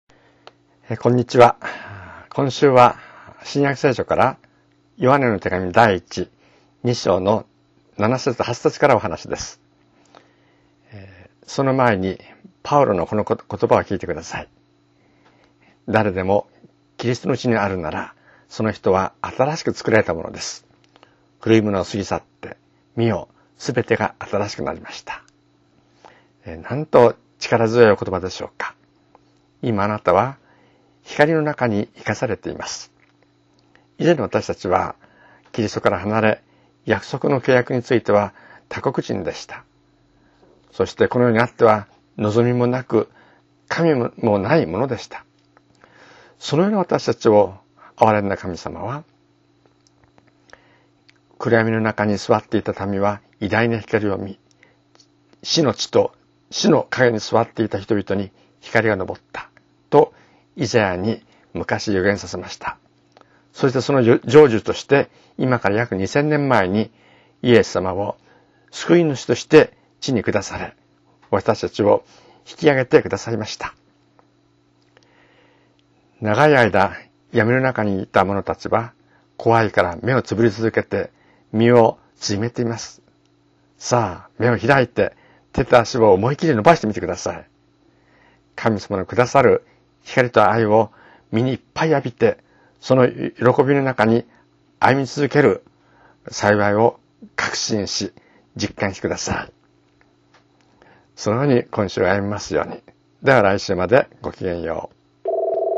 声のメッセージ